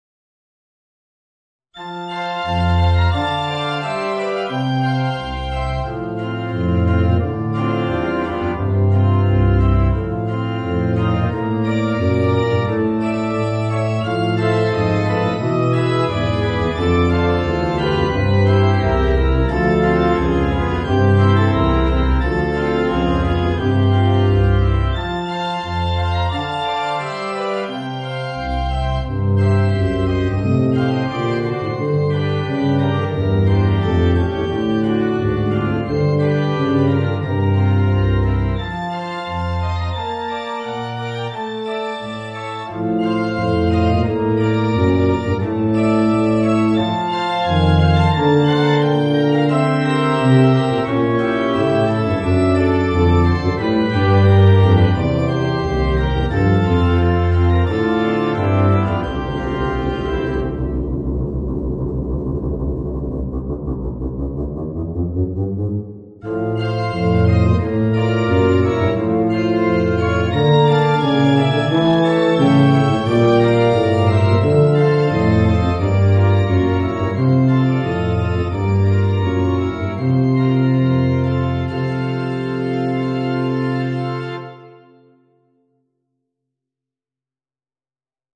Voicing: Bb Bass and Organ